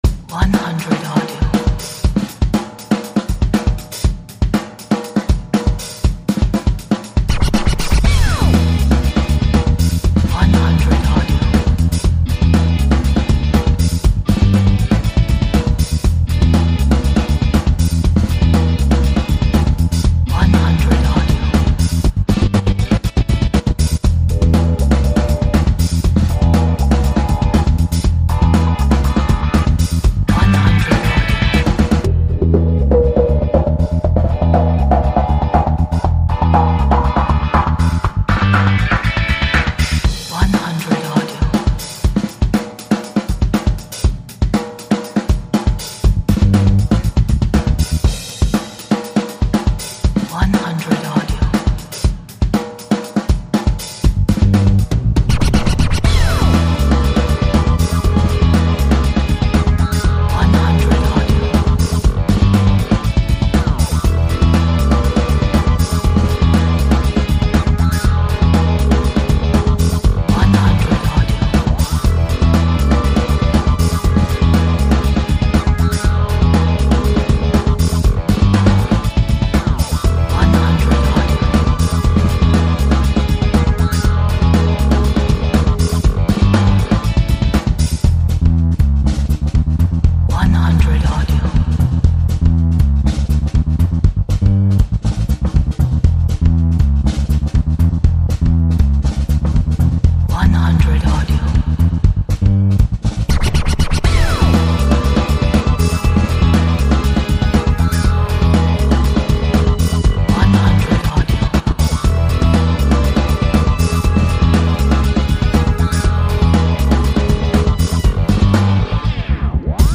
时髦 乐趣